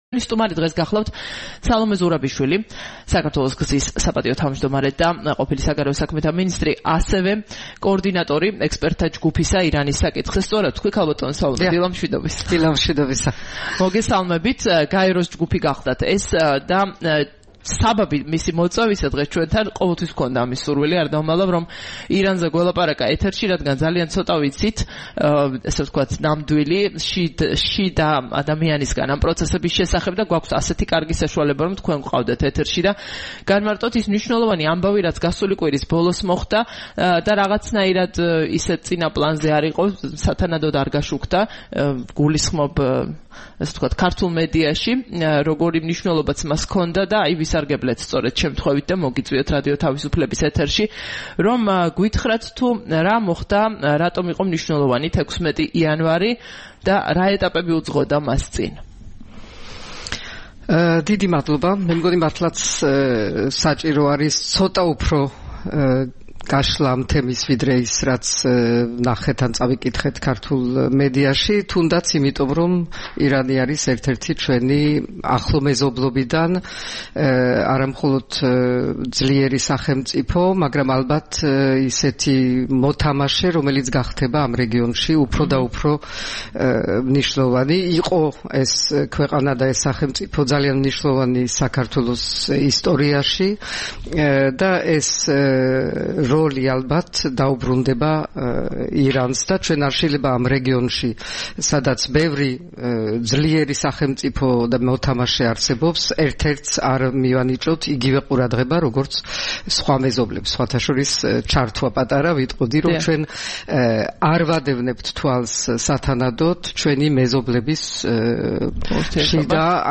საუბარი სალომე ზურაბიშვილთან